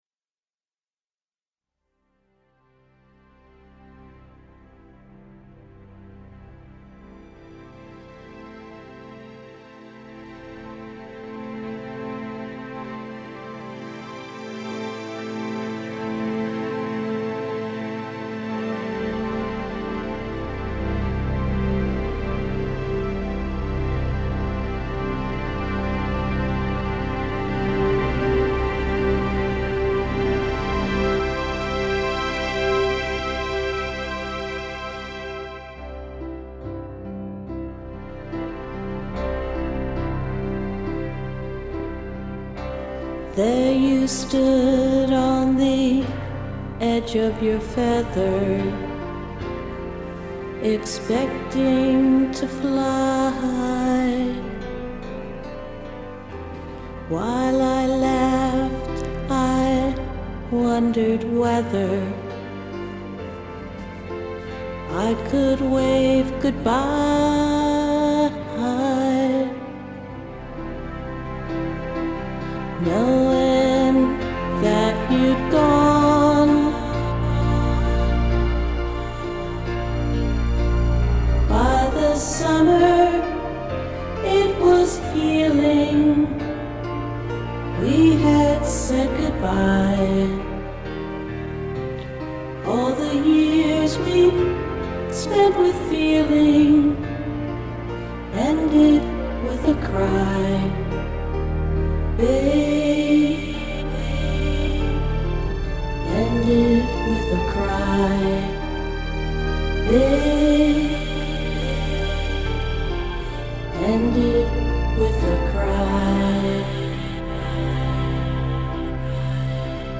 Vocals
Piano, Organ/Synth